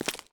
Footstep_Dirt_06.wav